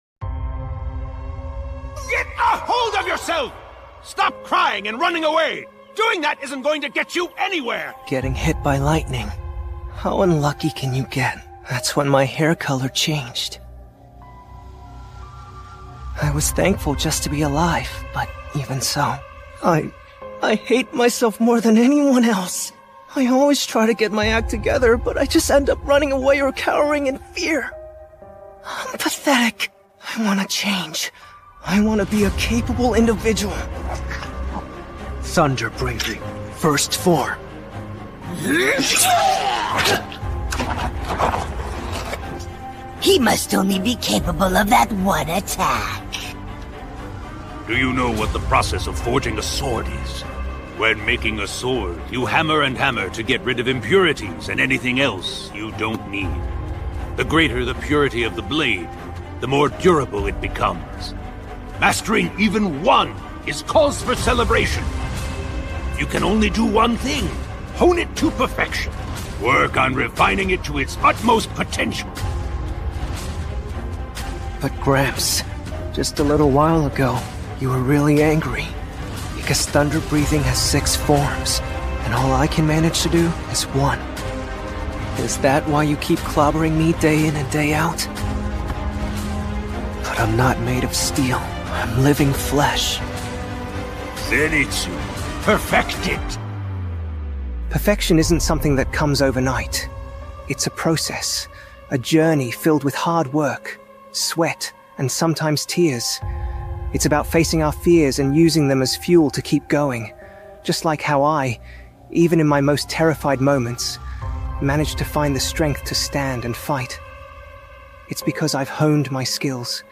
Become Your Best Version | Zenitsu Motivational Speech